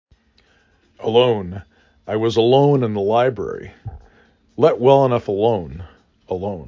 5 Letters, 2 Syllable
ə l O n